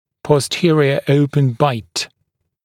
[pɔs’tɪərɪə ‘əupən baɪt][пос’тиэриэ ‘оупэн байт]боковой открытый прикус, задний открытый прикус